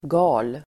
Uttal: [ga:l]